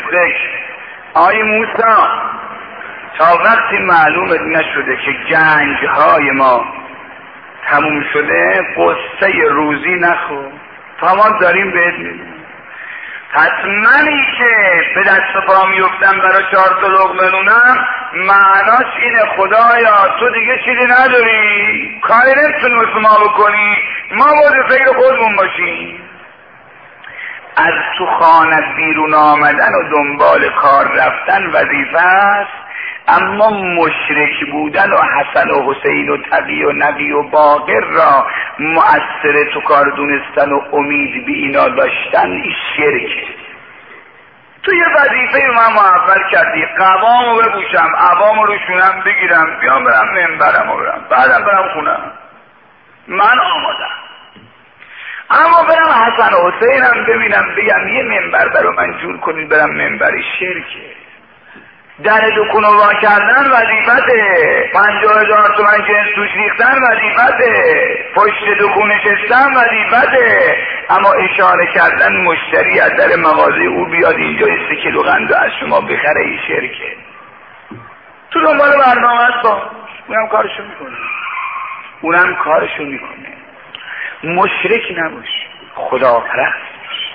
سخنرانی زیر یک سخنرانی حدودا یک دقیقه ای از شیخ احمد کافی هست